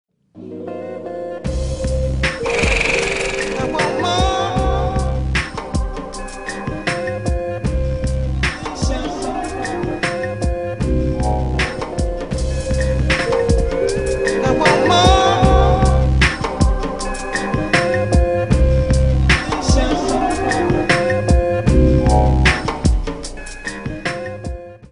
Чья-то самодеятельность.